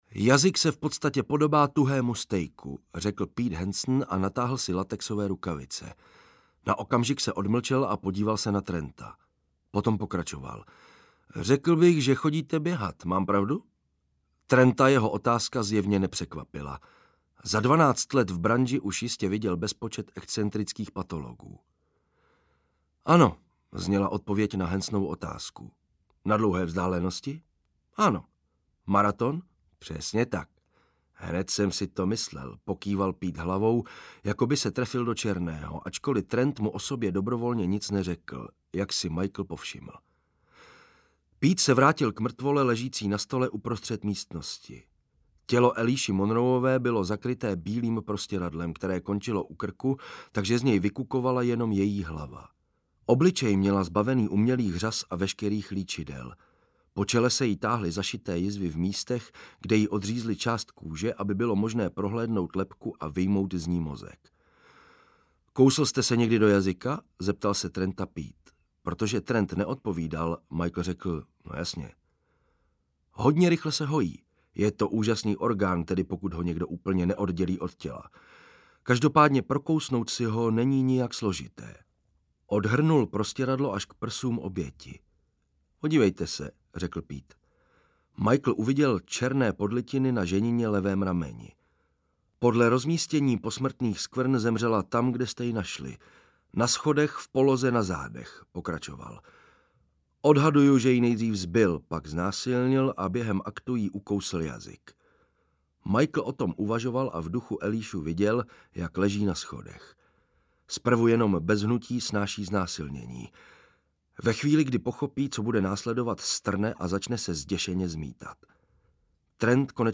Triptych audiokniha
Ukázka z knihy